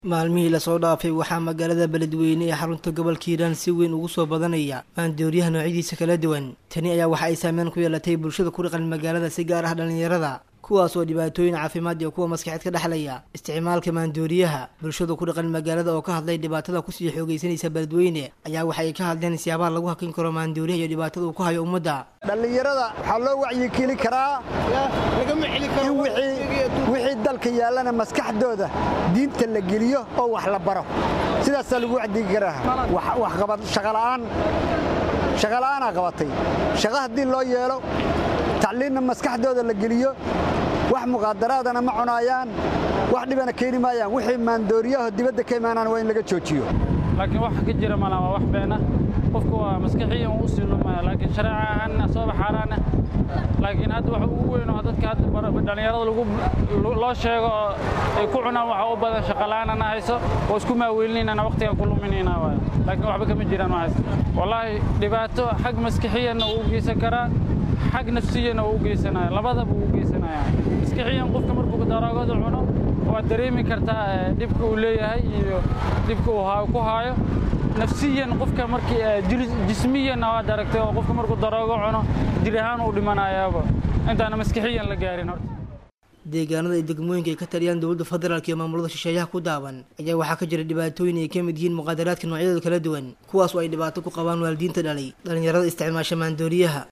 Bulshada ku dhaqan magaalada oo ka hadlay dhibaatadaan kusii xoogeysaneysa Baladweyne ayaa waxa ay ka warameen siyaabaha lagy hakin karo maandooriyaha iyo dhibaatada uu ku hayo umadda.